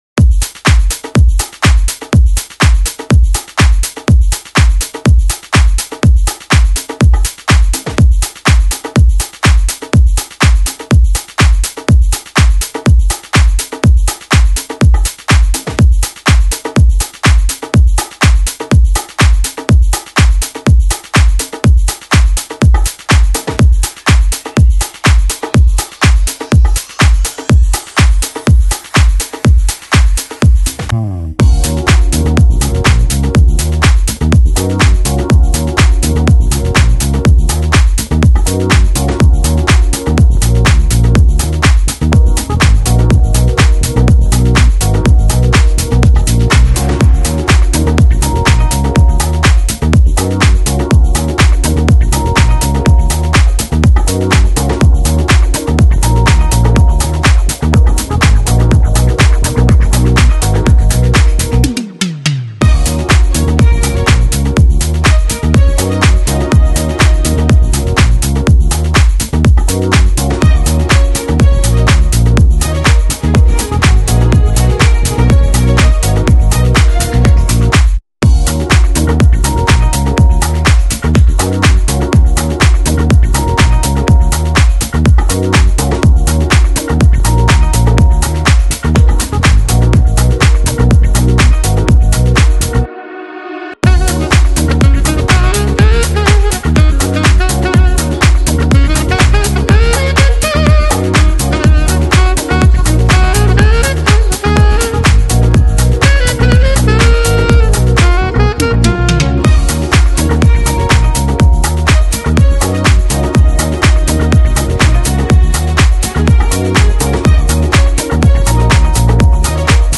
Жанр: Electronic, Deep House, Soulful House